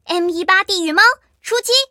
M18地狱猫编入语音.OGG